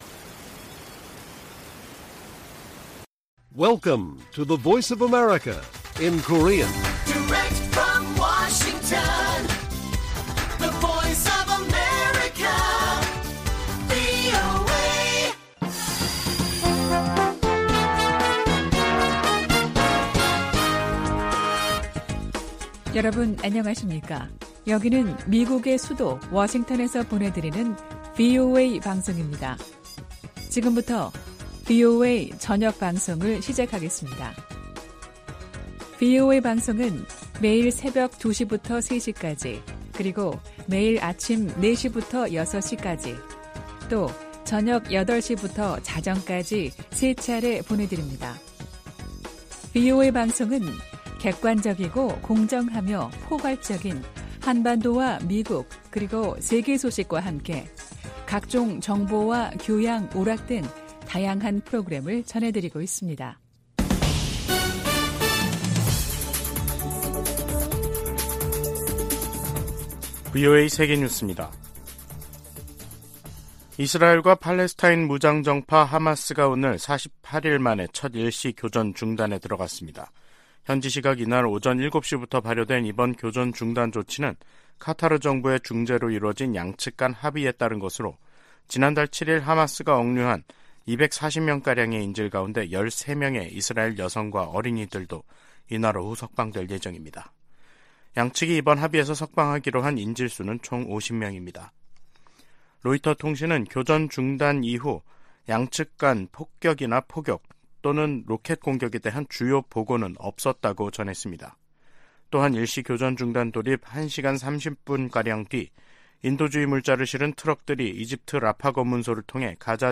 VOA 한국어 간판 뉴스 프로그램 '뉴스 투데이', 2023년 11월 24일 1부 방송입니다. 미국과 유럽연합(EU)이 국제원자력기구 정기이사회에서 북한의 무기개발을 규탄했습니다. 북한이 쏴 올린 정찰위성 만리경 1호가 빠른 속도로 이동하면서 하루에 지구를 15바퀴 돌고 있는 것으로 확인됐습니다. 한국-영국 정부가 북한 해킹 공격의 위험성을 알리는 합동주의보를 발표했습니다.